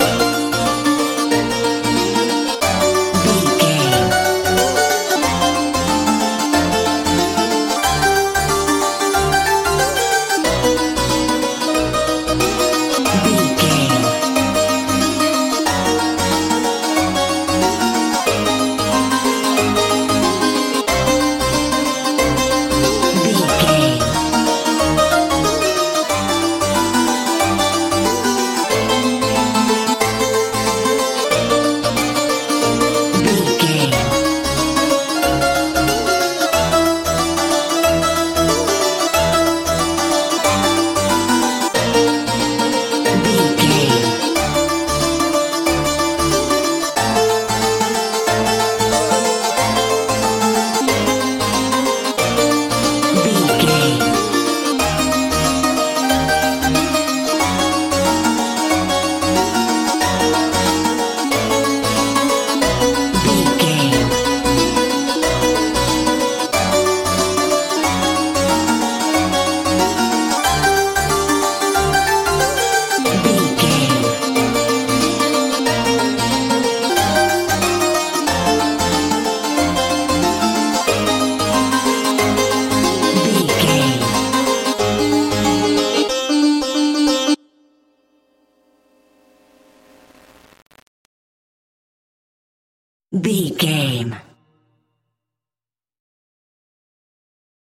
bollywood feel...bass santoor sitar used in song
Ionian/Major
D
dreamy
sweet